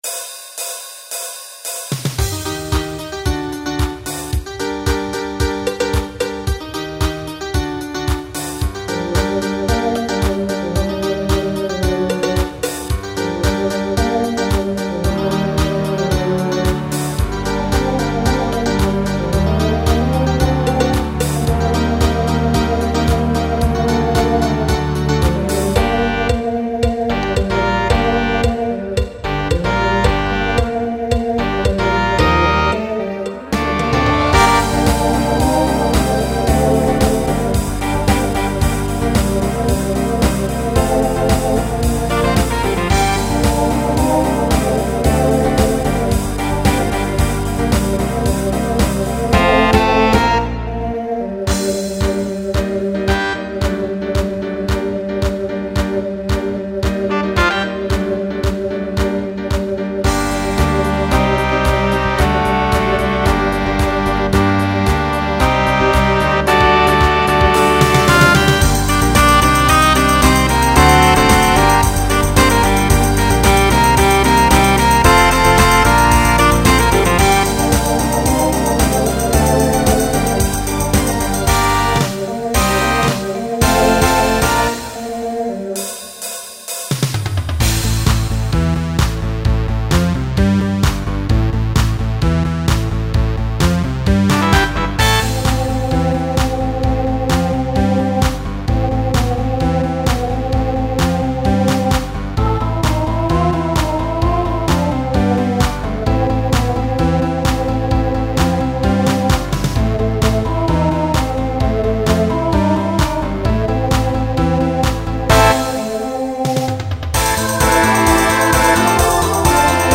Genre Pop/Dance
Voicing Mixed